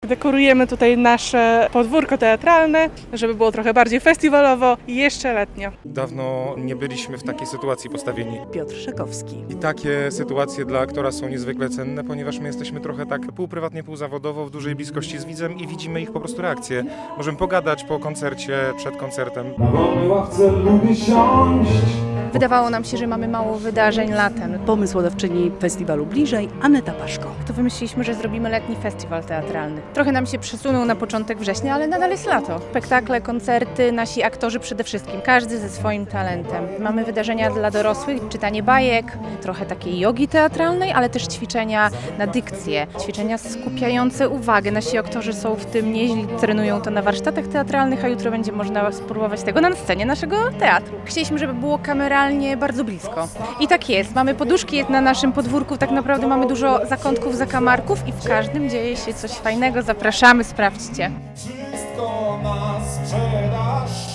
To dwa dni spektakli i koncertów - teatralnym korowodem rozpoczął się w stolicy województwa festiwal Bliżej - relacja